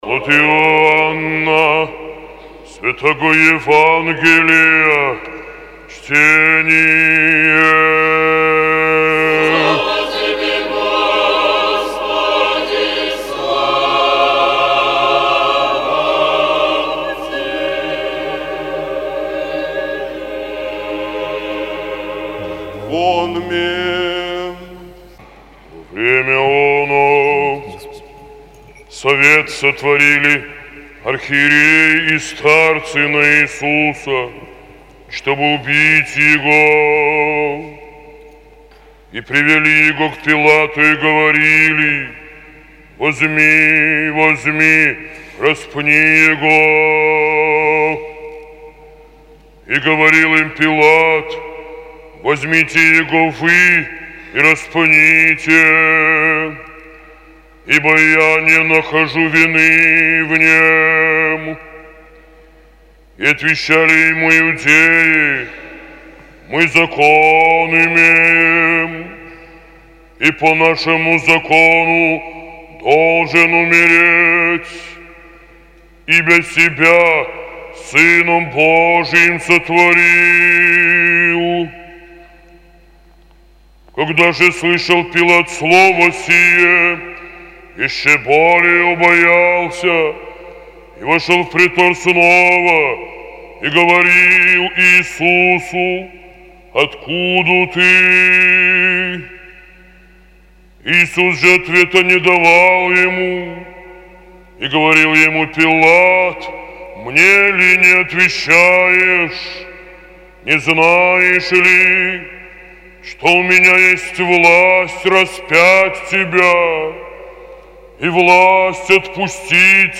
ЕВАНГЕЛЬСКОЕ ЧТЕНИЕ НА ЛИТУРГИИ